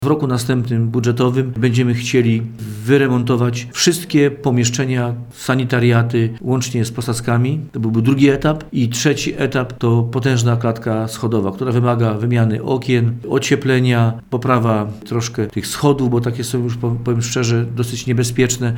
Jak tłumaczy wójt Kazimierz Skóra cała inwestycja została podzielona na trzy etapy.